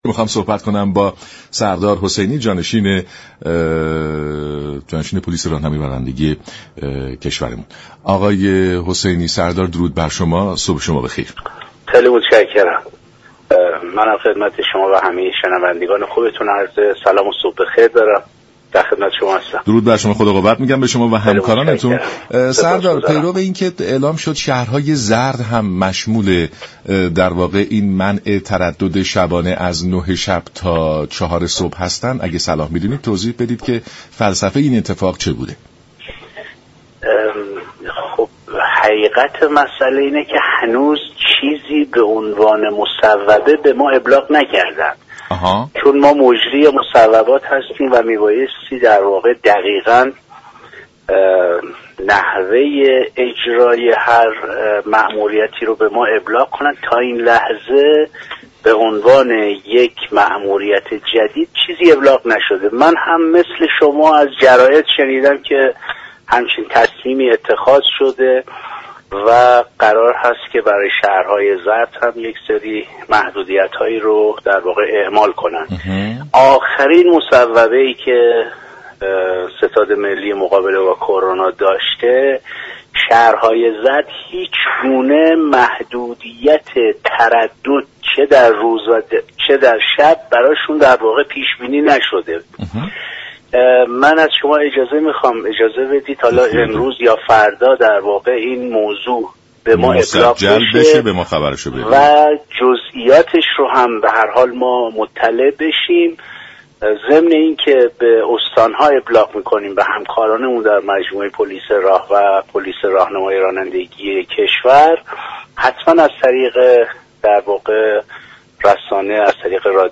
به گزارش شبكه رادیویی ایران، سردار سید تیمور حسینی جانشین پلیس راهنمایی و رانندگی در برنامه سلام صبح بخیر رادیو ایران در پاسخ به این پرسش كه آیا قانون ممنوعیت تردد شبانه خودرو پس از ساعت 9:00 تا 4:00 در شهرهای زرد قابل اعمال است یا خیر؟گفت: در این خصوص هنوز مصوبه ای به پلیس راهنمایی و رانندگی ابلاغ نشده است.